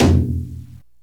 normal-sliderwhistle.ogg